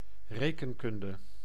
Ääntäminen
Synonyymit cijferen cijferkunst aritmetica rekenkunst Ääntäminen Haettu sana löytyi näillä lähdekielillä: hollanti Käännös Ääninäyte Substantiivit 1. arithmétique {f} Paris France Suku: f .